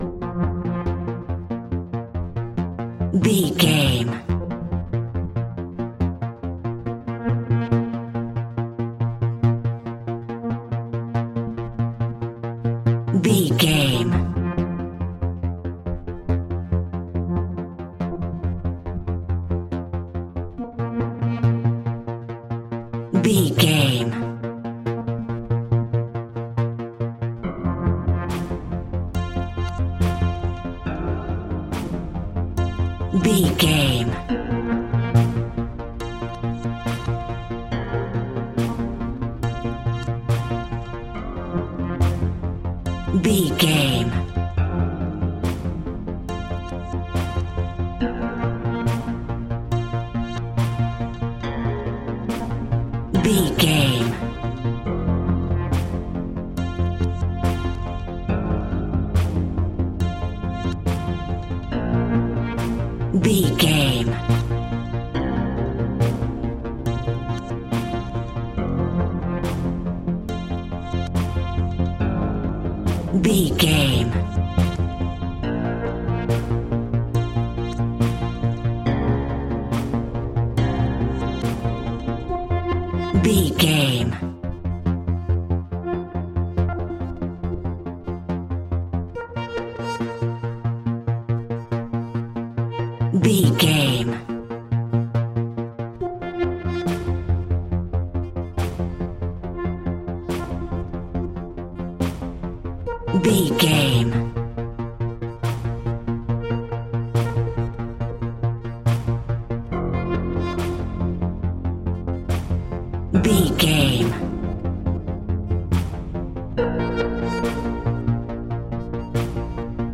In-crescendo
Thriller
Aeolian/Minor
ominous
dark
eerie
synthesizer
horror music
Horror Pads
Horror Synths